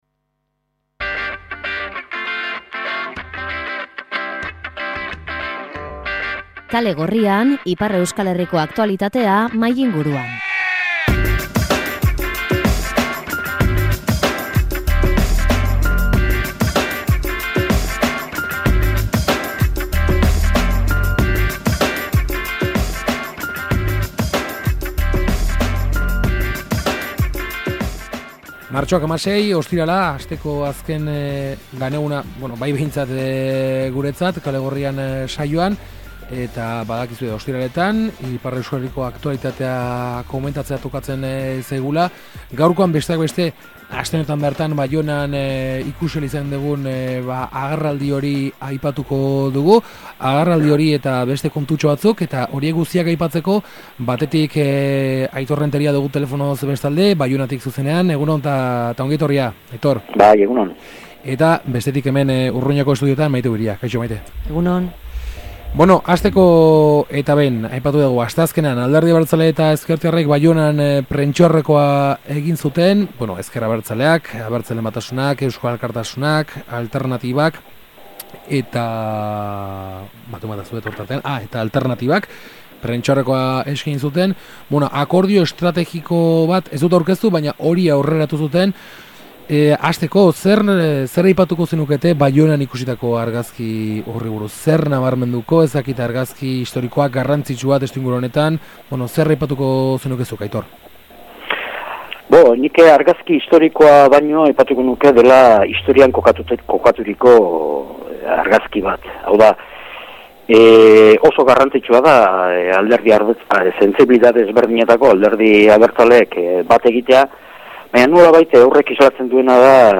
Ipar Euskal Herriko aktualitatea mahai-inguruan